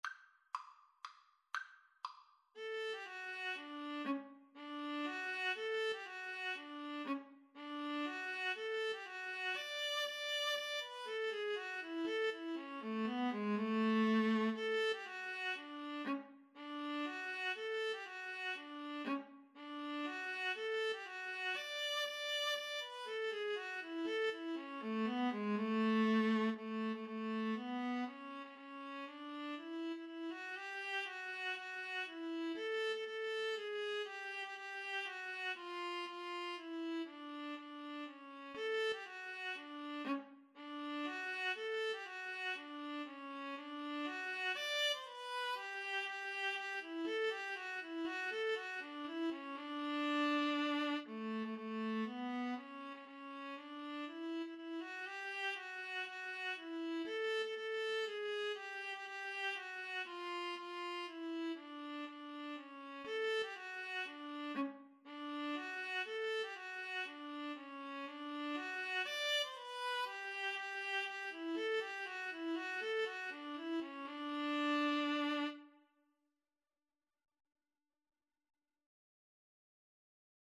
3/4 (View more 3/4 Music)
Allegro (View more music marked Allegro)
Classical (View more Classical Viola-Cello Duet Music)